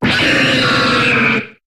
Cri de Cobaltium dans Pokémon HOME.